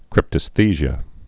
(krĭptəs-thēzhə, -zhē-ə)